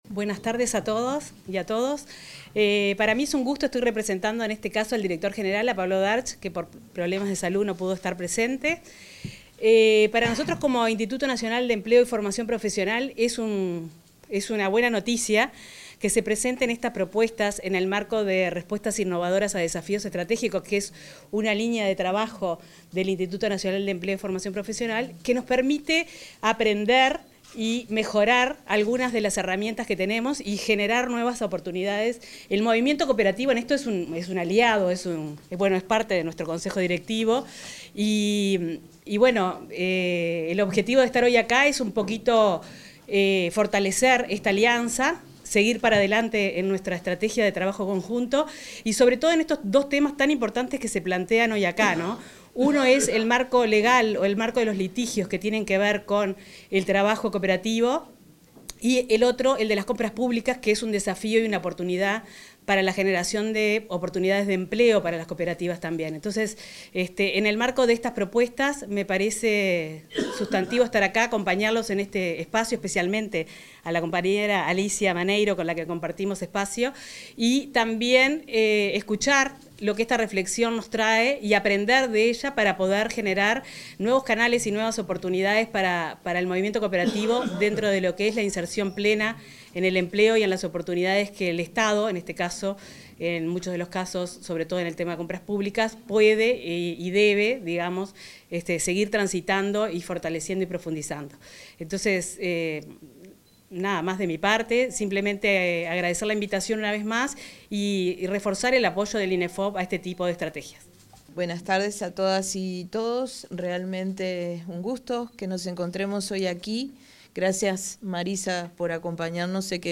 Acto por la presidentación de estudios sobre trabajo cooperativo
Acto por la presidentación de estudios sobre trabajo cooperativo 23/07/2024 Compartir Facebook X Copiar enlace WhatsApp LinkedIn Est 23 de julio, los institutos nacionales de Empleo y Formación Profesional (Inefop), de Cooperativismo (Inacoop) y la Confederación Uruguaya de Entidades Cooperativas (Cudecoop) presentaron dos investigaciones en el marco del Programa de Formación Cooperativa. Participaron del evento la directora del Inefop, Marisa Acosta, y el presidente de Inacoop, Martín Fernández.